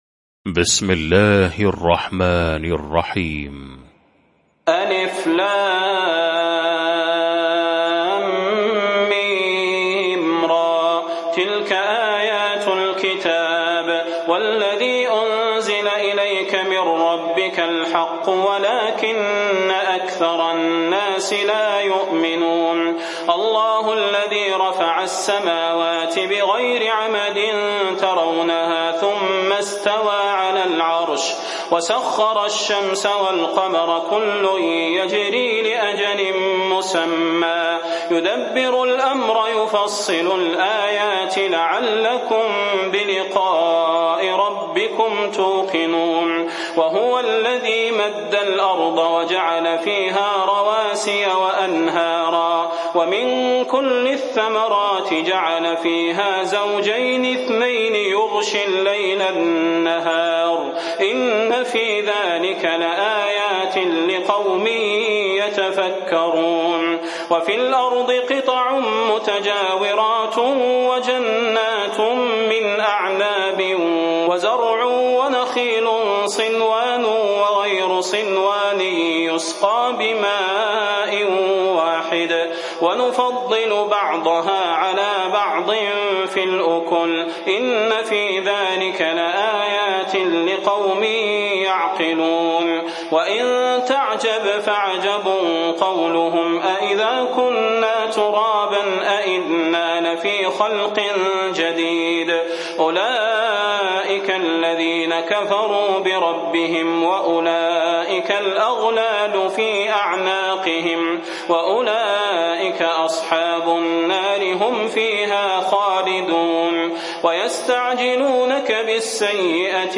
المكان: المسجد النبوي الشيخ: فضيلة الشيخ د. صلاح بن محمد البدير فضيلة الشيخ د. صلاح بن محمد البدير الرعد